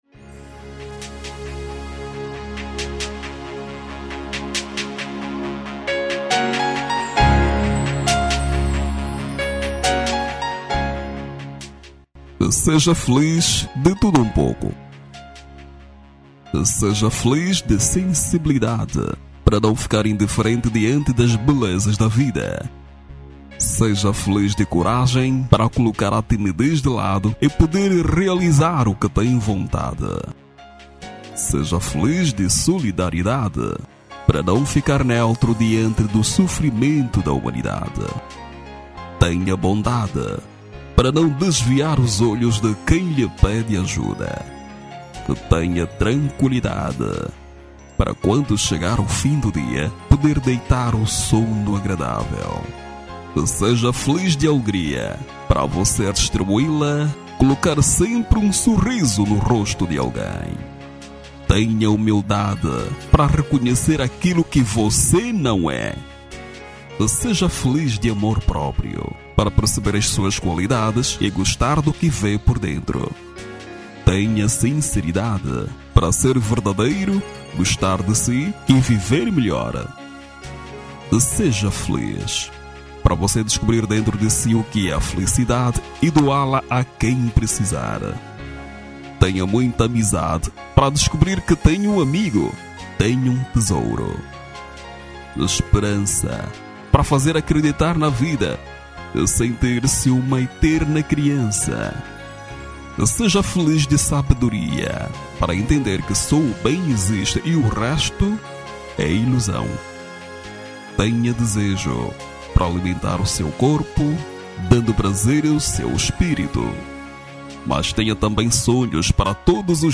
Masculino
Seja Feliz - Mensagem Motivacional